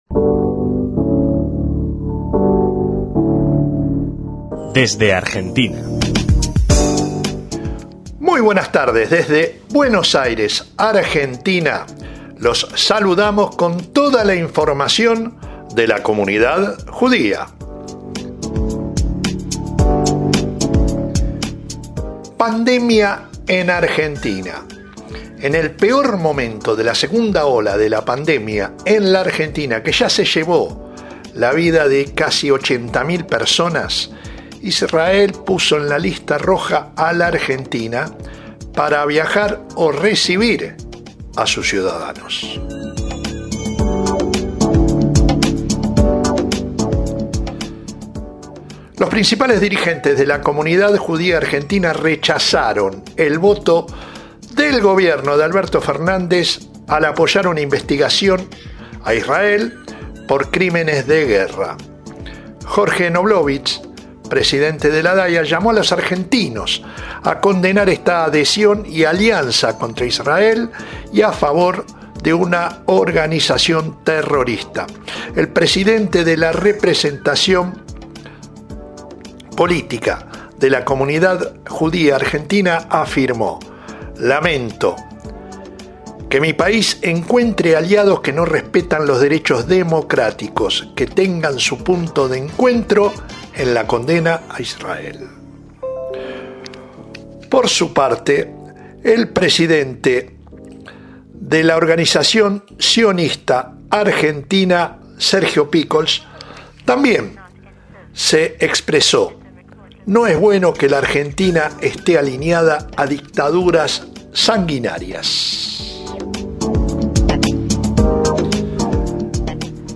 DESDE ARGENTINA, CON VIS A VIS